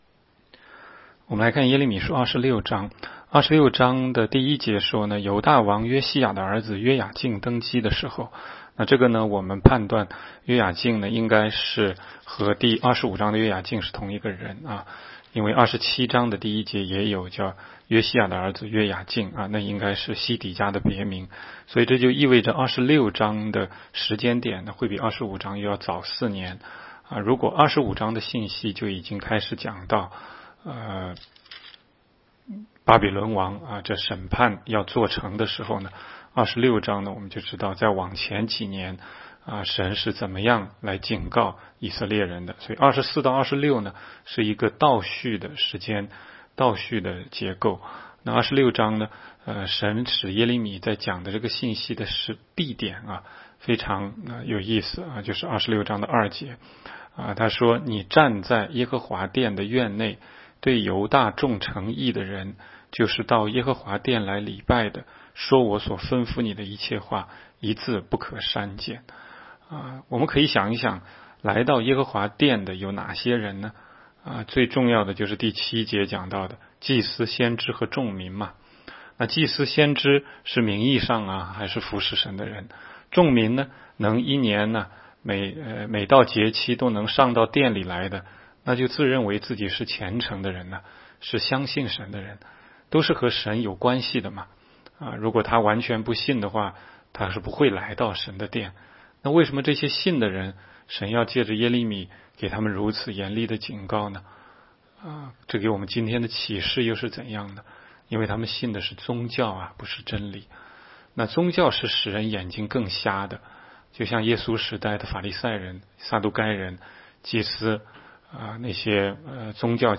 16街讲道录音 - 每日读经 -《耶利米书》26章